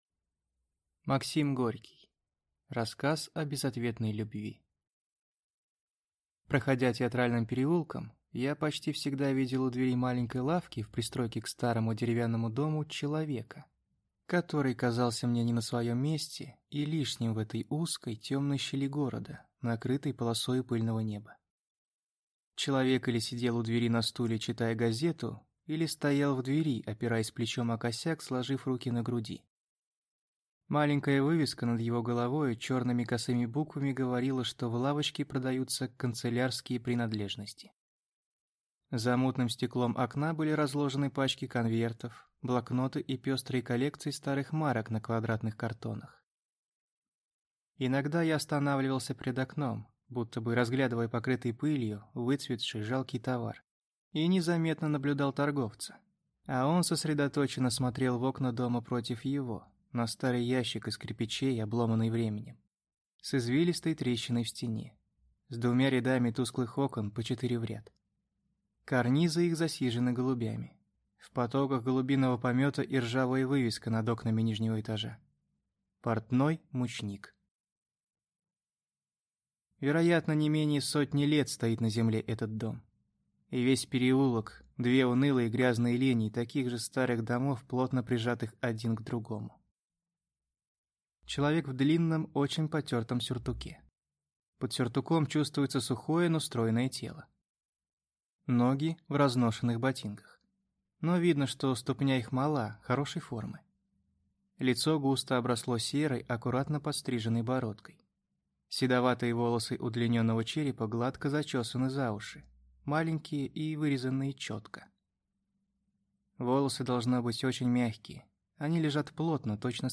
Аудиокнига Рассказ о безответной любви | Библиотека аудиокниг